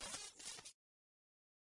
环境 " 草地上的沙沙声
描述：草沙沙作响。用变焦记录器记录沙沙叶，并在收割机中编辑它们。
Tag: 风能 沙沙作响 树叶